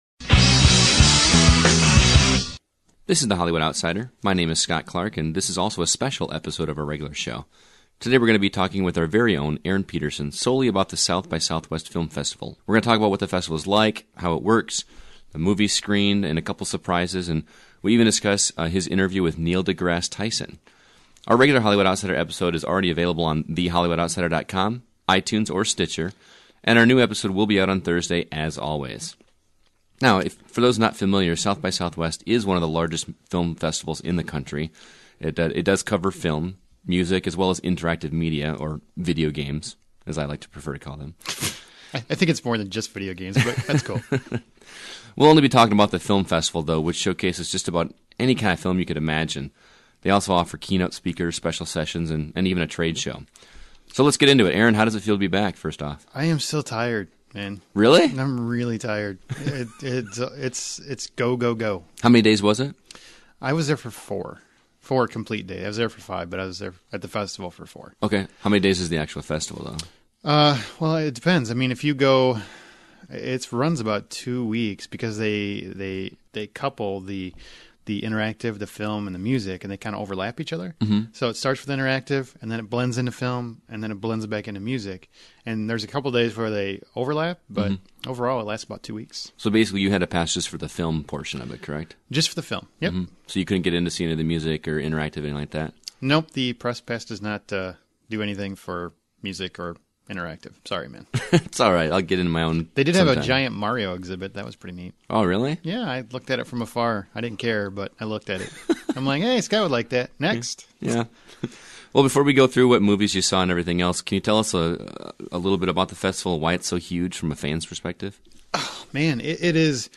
Bad Words, Neighbors, Joe, The Guest and many other films we discuss on this episode, as well as you will get an insiders take from our own exclusive interview with Cosmos Host, Neil deGrasse Tyson!